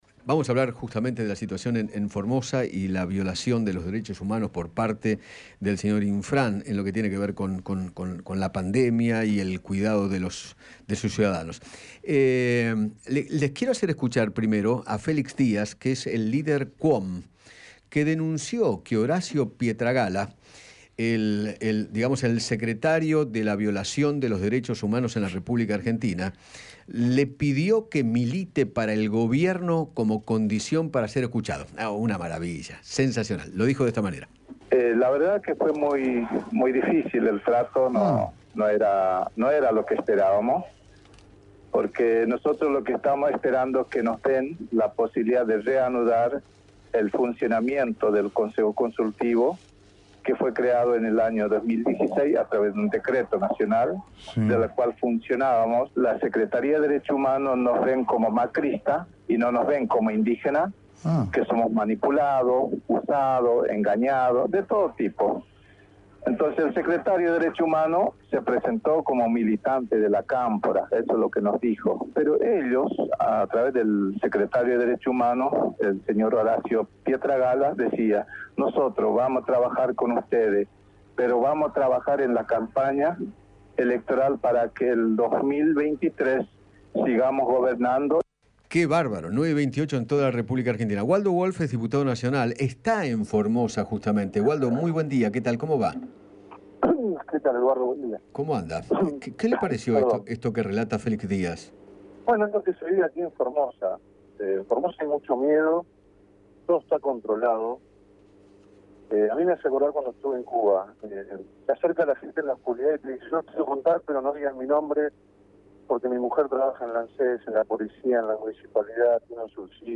Waldo Wolff, diputado nacional, dialogó con Eduardo Feinmann sobre lo que está sucediendo en aquella provincia, donde se encuentra para constatar las denuncias de violación a los derechos humanos.